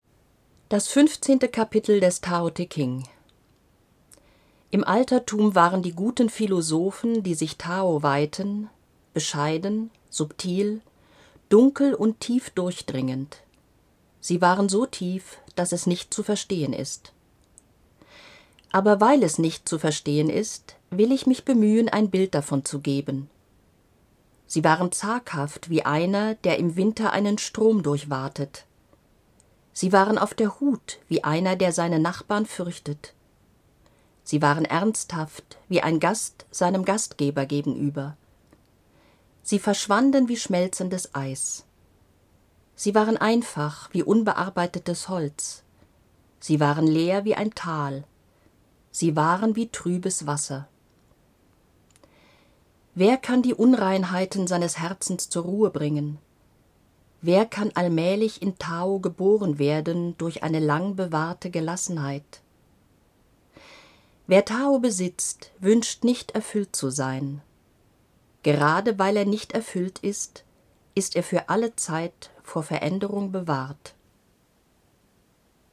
Die CD "Die Chinesische Gnosis" ist ein Hörbuch, das dem Text des Buches eine zusätzliche Lebendigkeit verleiht und dem Zuhörer ein direktes Erlebnis wie in einem vertrauten persönlichen Gespräch vermittelt.